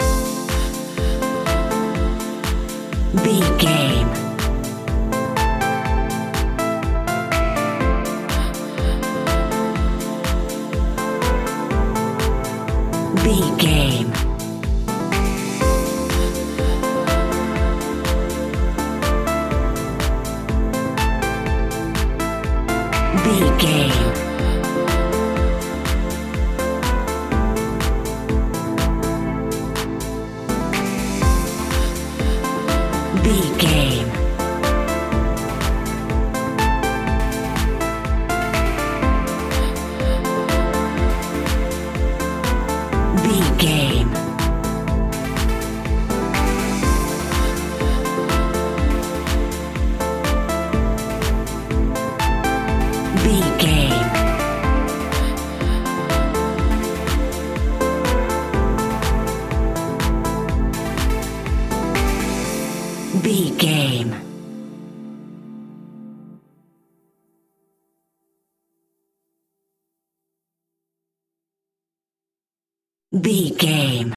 royalty free music
Aeolian/Minor
groovy
smooth
uplifting
drum machine
electro house
funky house
synth leads
synth bass